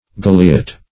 Search Result for " galiot" : The Collaborative International Dictionary of English v.0.48: Galiot \Gal"i*ot\, n. [OE. galiote, F. galiote.